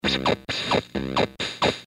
突兀的高帽
描述：简单的突发高帽循环。电音电子的突变节拍
标签： 132 bpm Glitch Loops Drum Loops 313.38 KB wav Key : Unknown
声道立体声